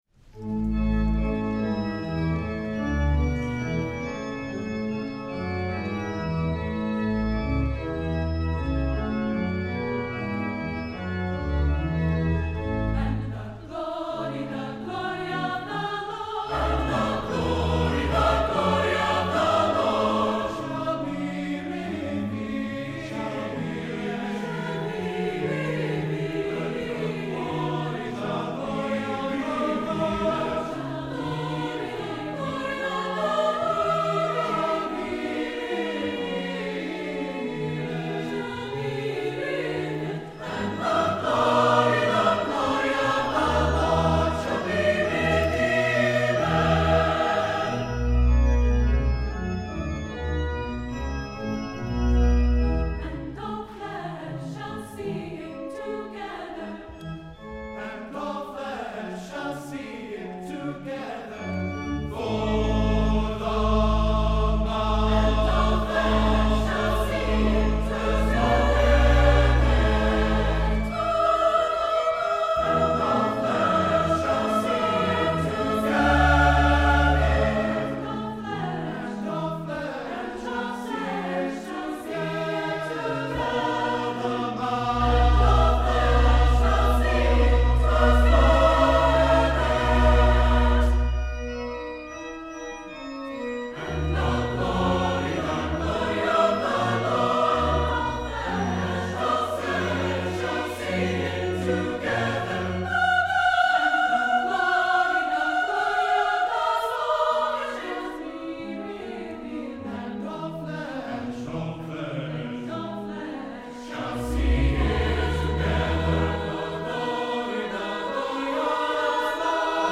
Christmas Praise with The Genevans - Live from Beaver Falls